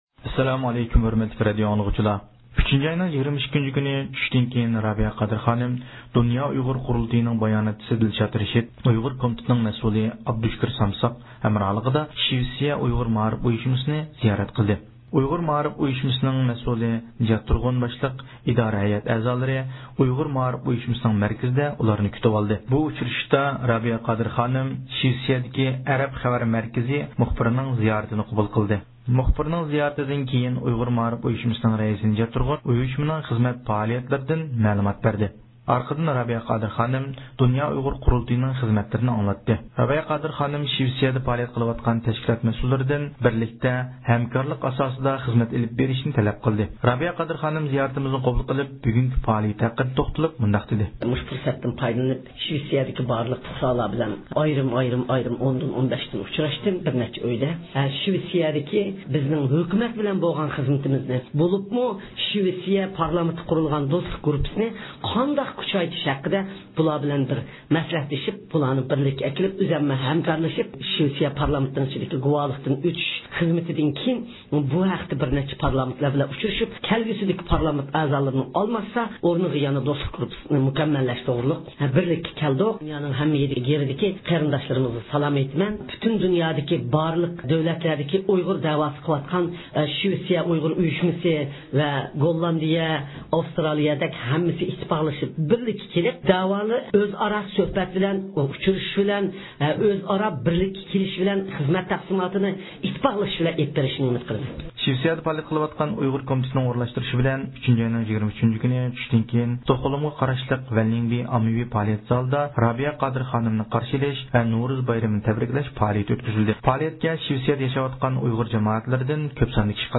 رابىيە قادىر خانىم زىيارىتىمىزنى قوبۇل قىلىپ، بۈگۈنكى پائالىيىتى ھەققىدە توختالدى.